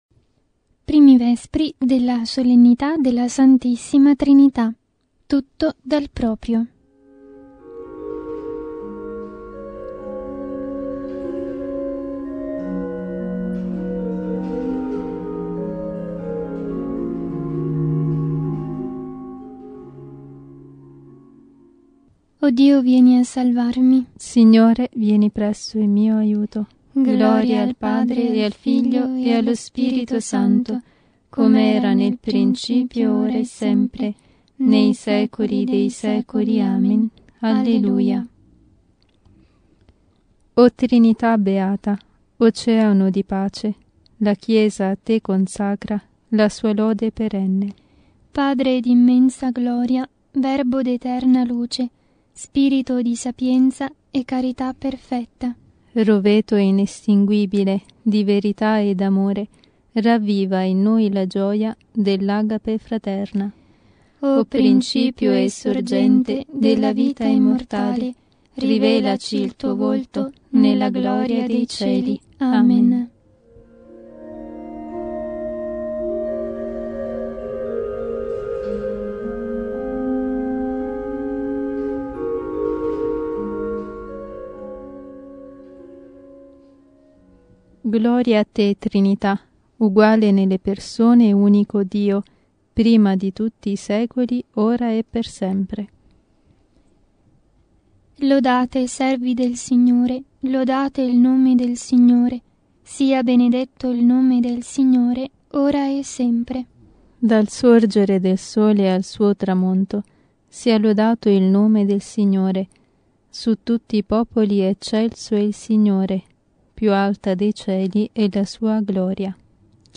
Liturgia delle Ore